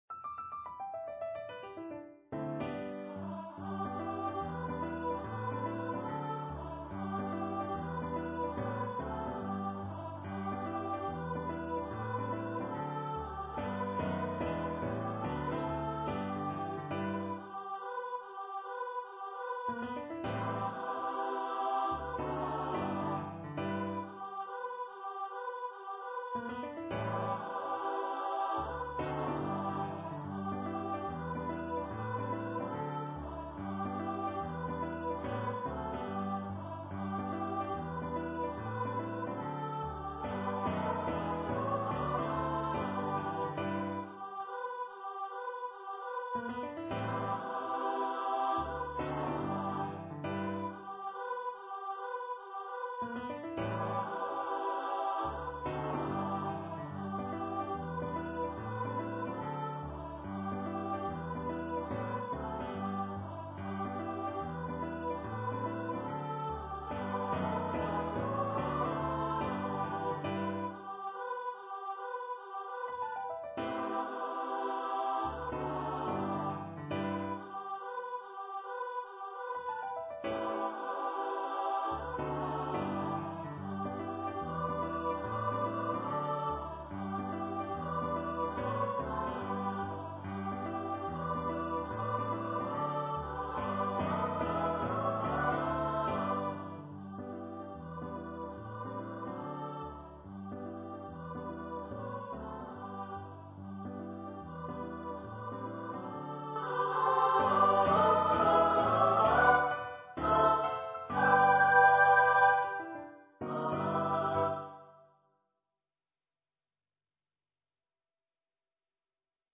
for female voice choir and piano